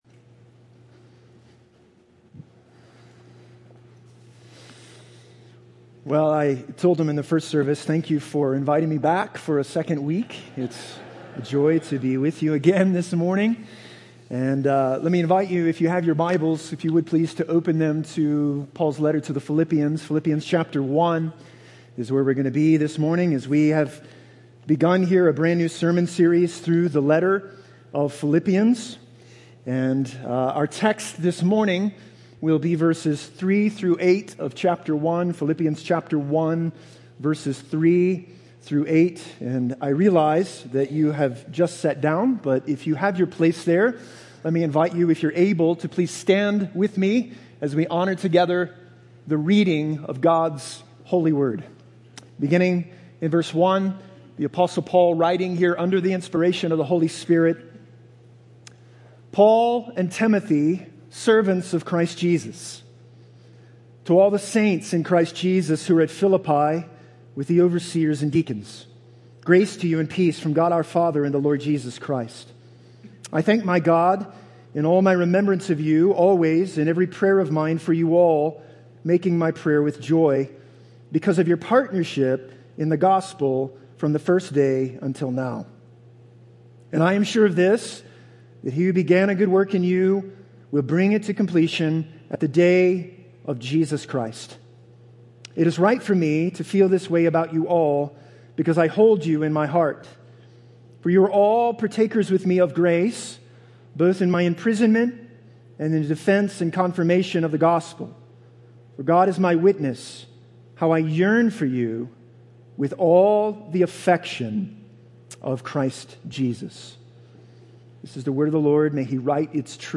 Sermons - Vista Grande Baptist Church